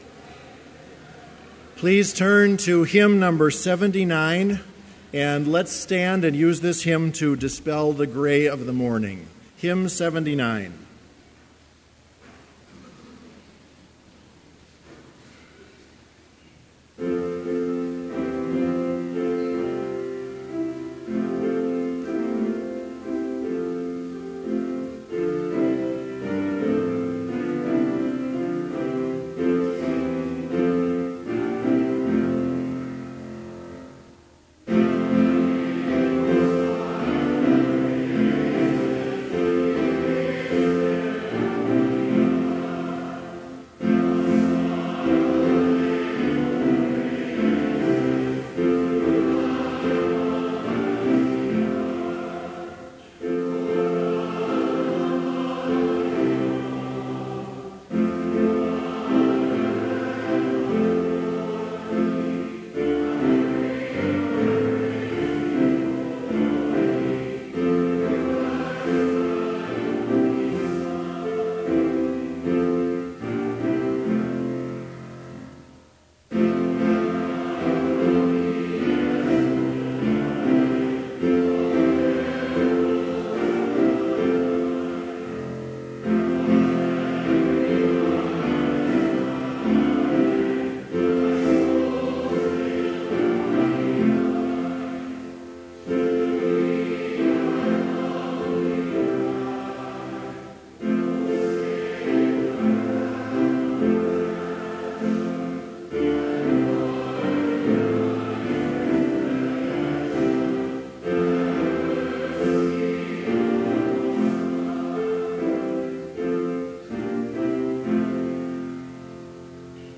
Complete service audio for Chapel - February 16, 2016